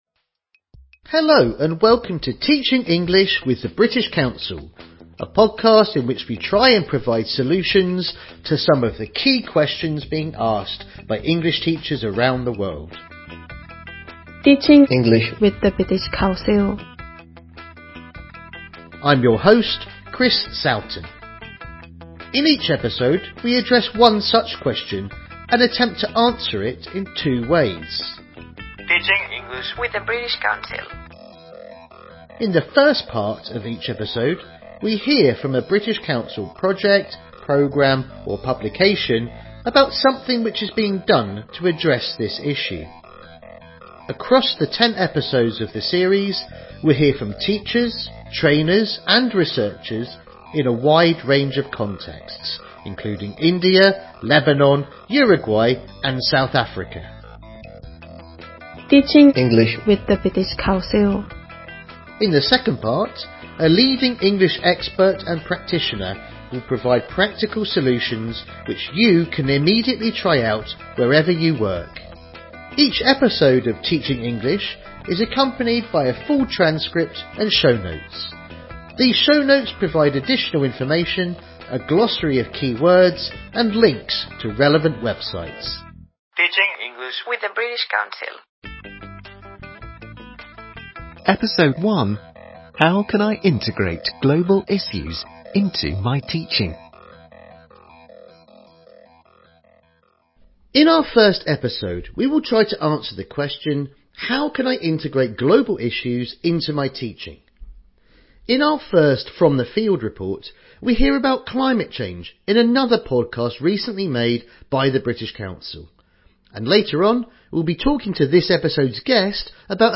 British Council: TeachingEnglish - series overview In this ten-part podcast series from the British Council, we try and provide solutions to some of the key questions being asked by English teachers around the world. Each episode explores a specific topic through interviews, a focus on recent developments and reports on British Council initiatives in English language teaching.